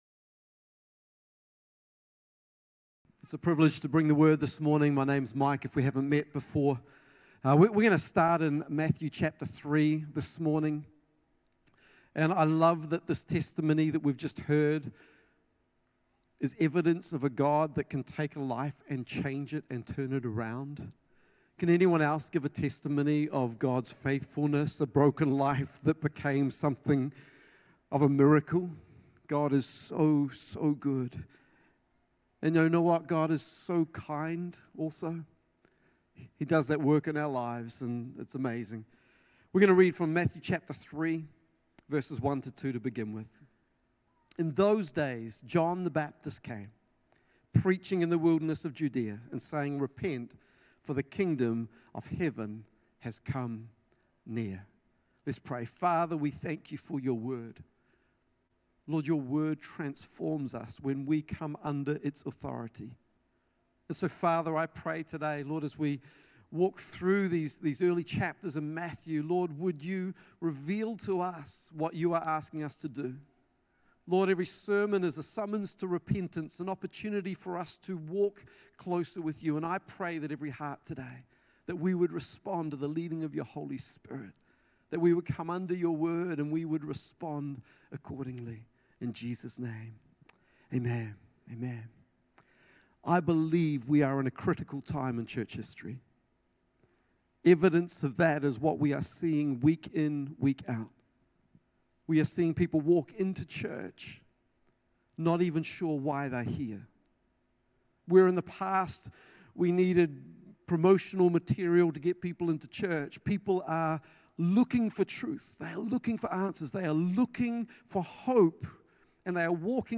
Pentecost Sunday